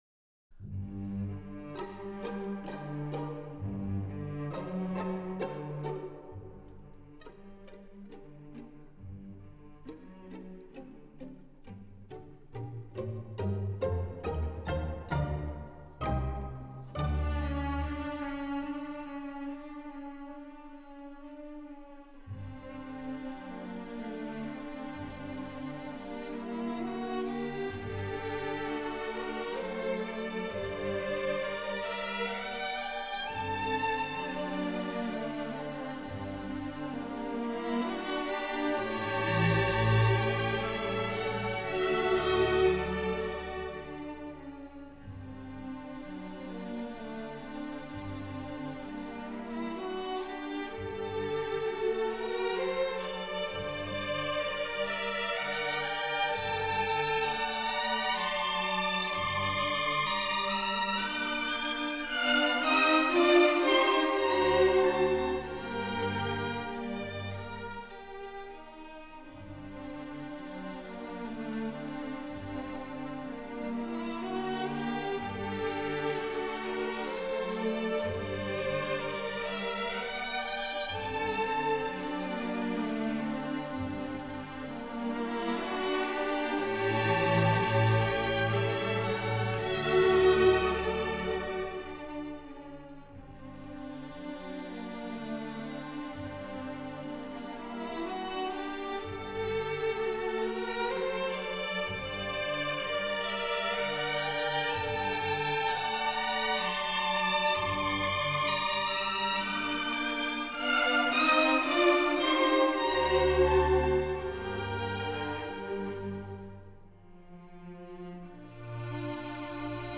바실리 칼리니코프-현을 위한 세레나데/예프게니 스베틀라노프(지휘) 러시아 국립 교향악단
Eugeniy Svetlanovr (cond)  The USSR State Symphony Orchestra